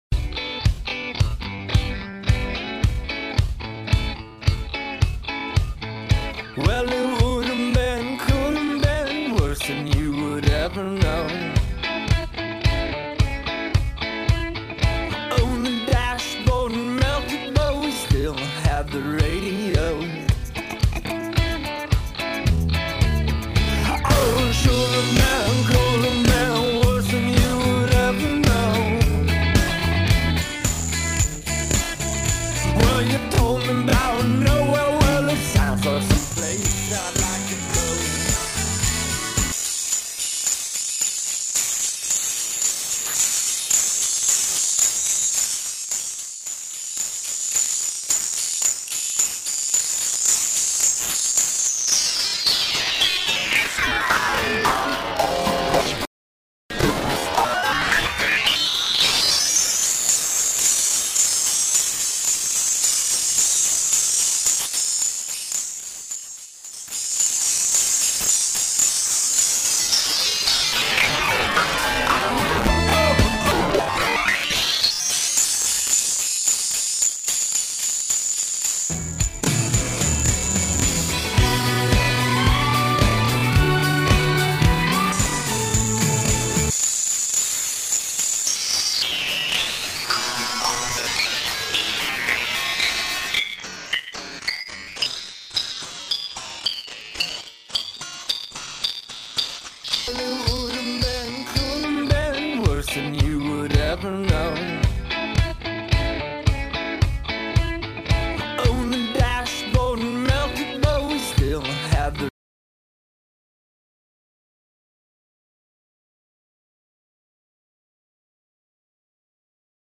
VST effect plugin